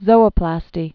(zōə-plăstē)